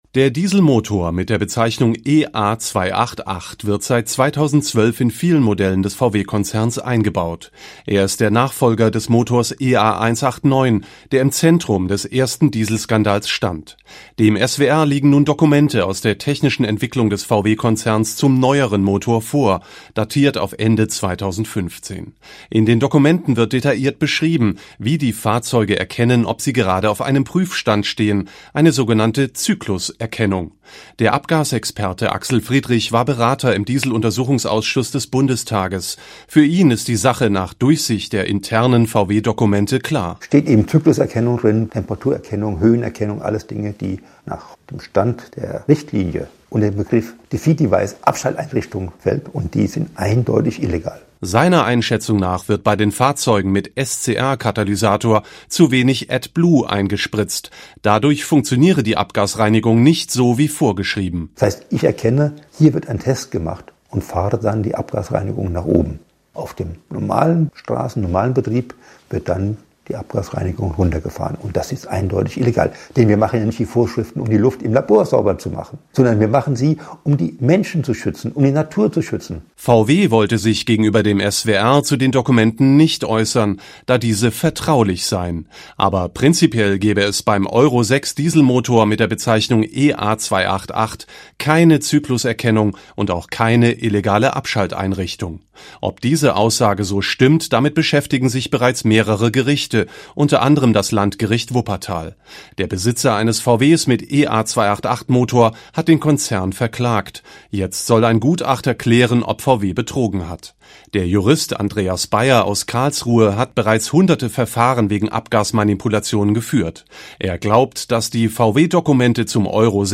Hören Sie hier den Original-Beitrag des SWR zum Thema „Abgasskandal: Neuere VW-Motoren unter Verdacht“ vom 12.09.2019: